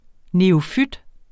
neofyt substantiv, fælleskøn Bøjning -ten, -ter, -terne Udtale [ neoˈfyd ] Oprindelse af græsk neophytos 'nyplantet' af neo- og phyton 'plante' Betydninger 1.